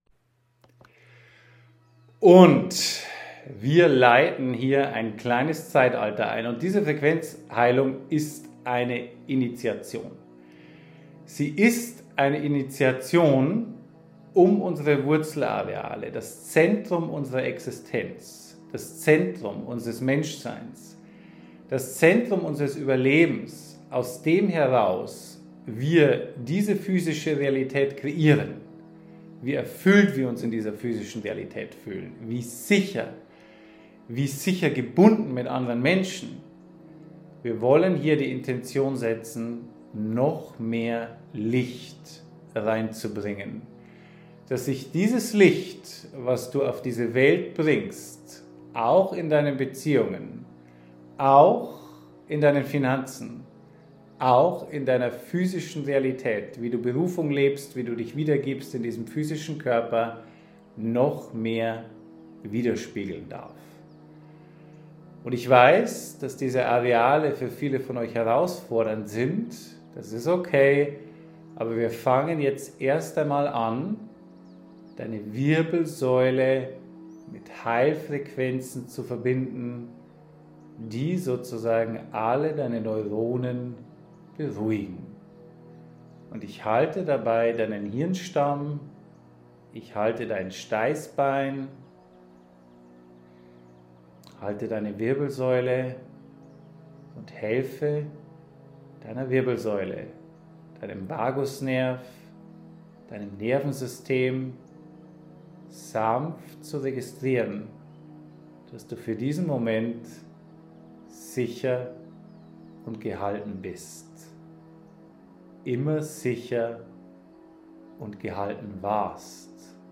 Wurzelshift_Frequenzheilung_MUSIK.mp3